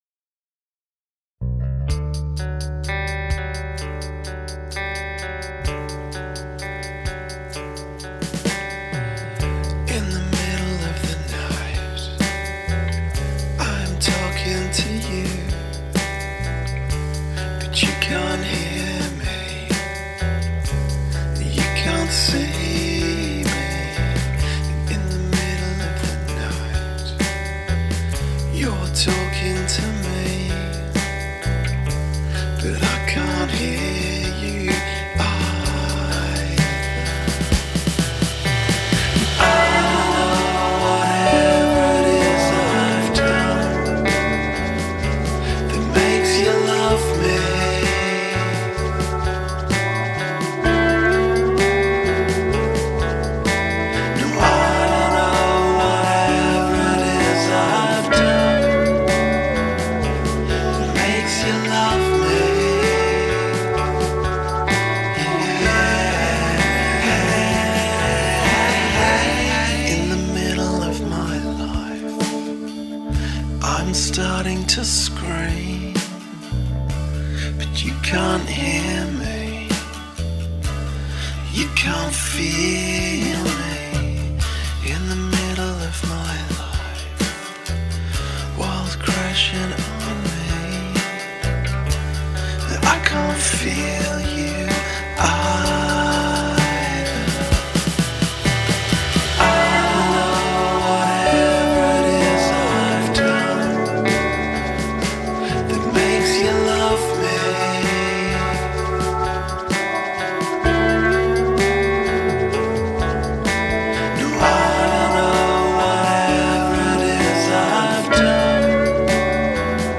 Hand percussion
Bass sounds good. I like the doubled vocals. Dreamy sounds.